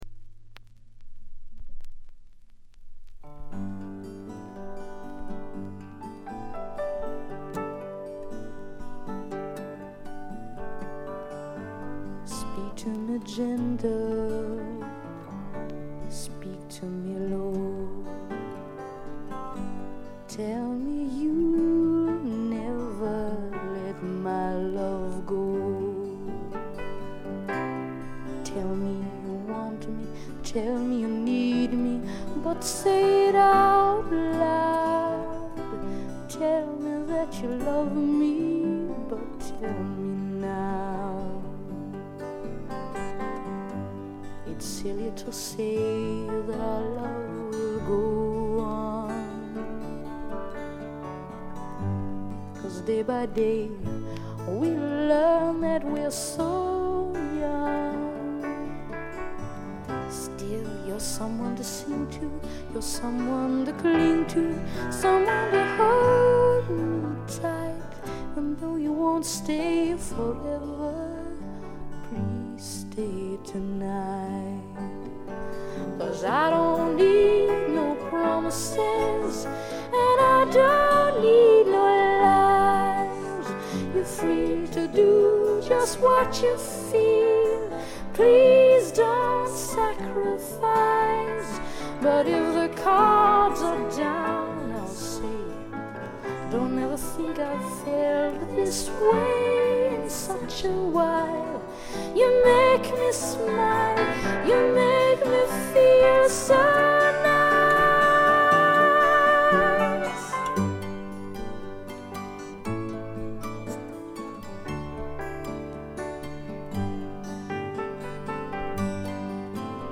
静音部でバックグラウンドノイズ、チリプチ少々、散発的なプツ音少し。
ほとんど弾き語りのような曲が多いのもよいですね。
試聴曲は現品からの取り込み音源です。